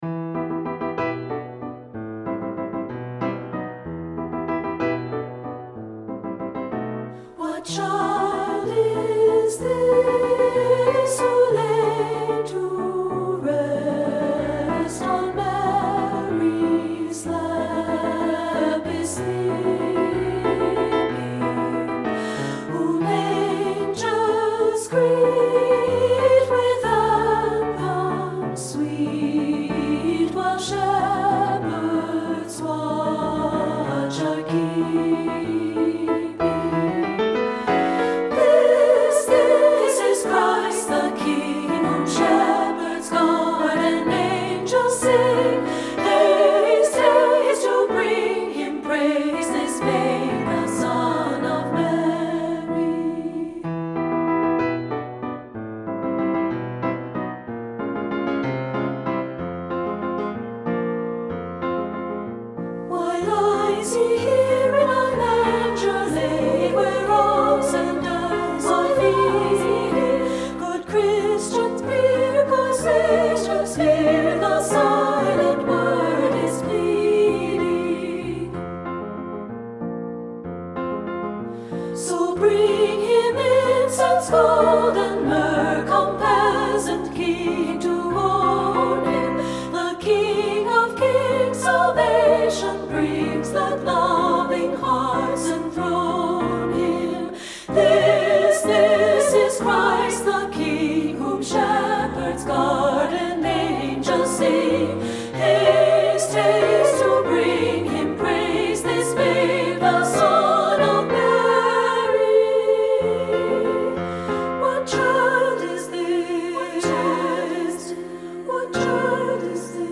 • Soprano
• Alto
• Piano
Studio Recording
arranged for SA choir and piano
up-tempo arrangement
Ensemble: Unison and Two-Part Chorus
Accompanied: Accompanied Chorus